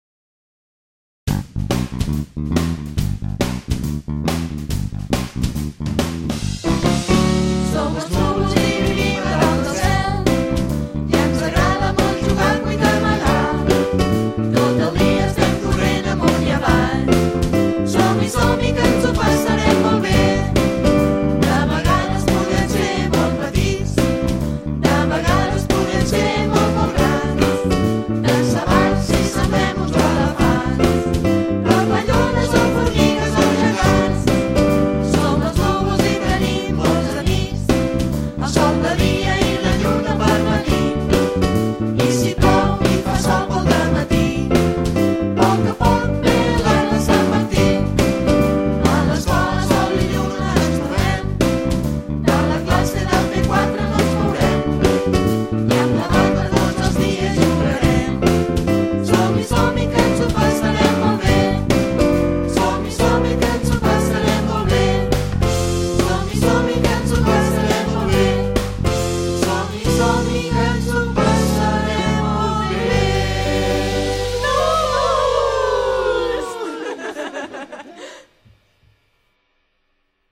Els hi ha agradat molt, ja que és una cançó molt alegra, divertida i bonica!!